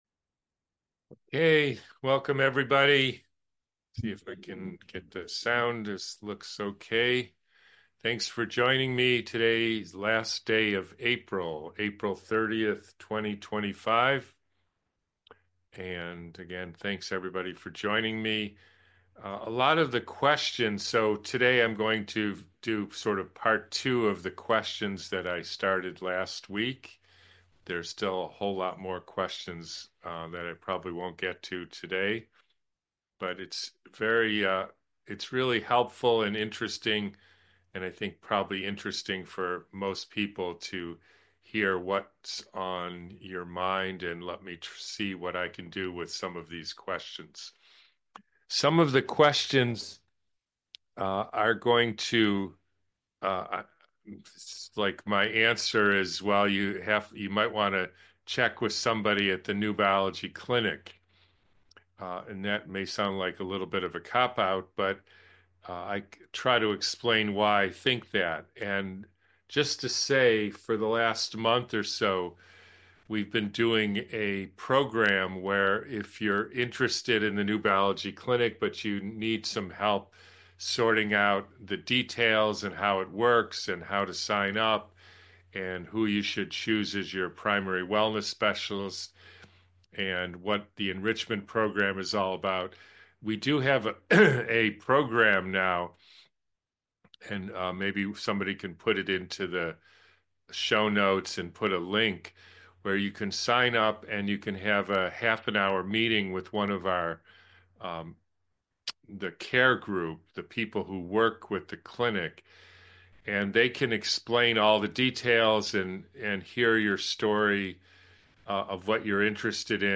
QA Webinar from 4/30/25